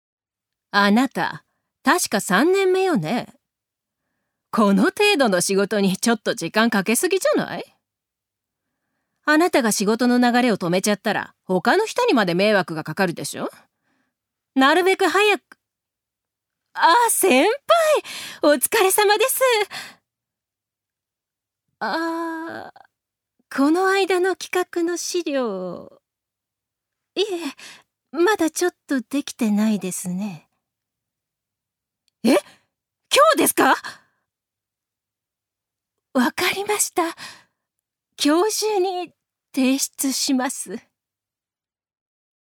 女性タレント
セリフ１